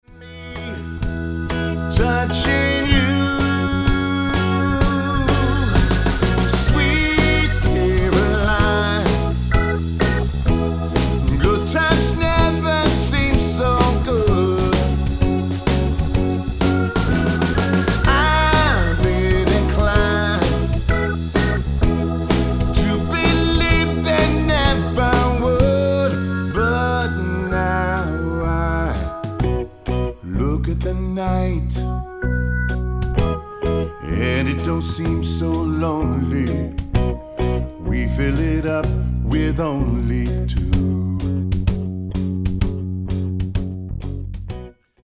Recueil pour Batterie